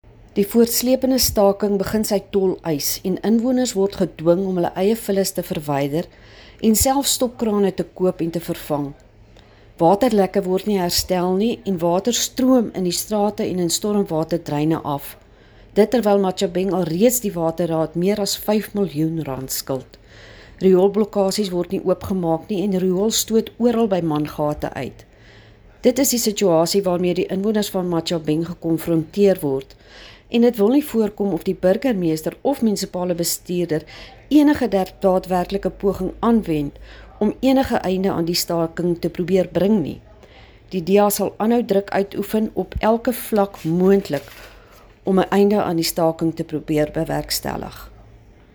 Afrikaans soundbites by Cllr Maxie Badenhorst.